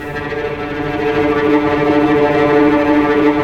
Index of /90_sSampleCDs/Roland LCDP08 Symphony Orchestra/STR_Vcs Bow FX/STR_Vcs Trem wh%